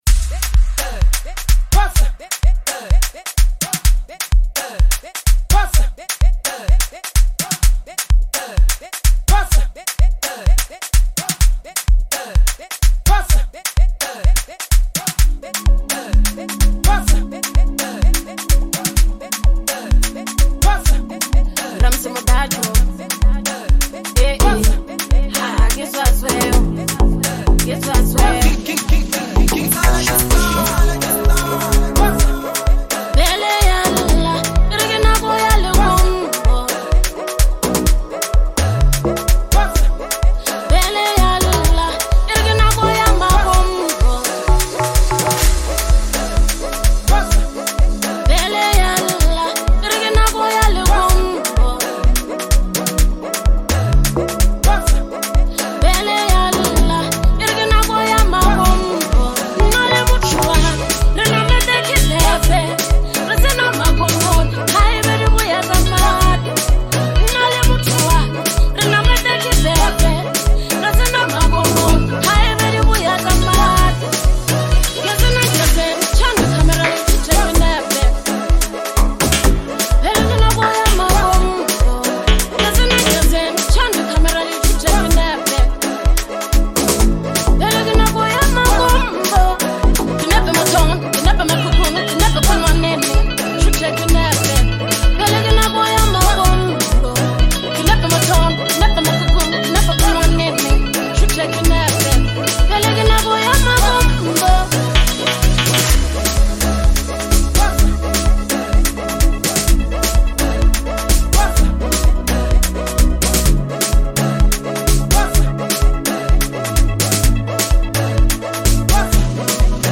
vibrant and uplifting Afro-house track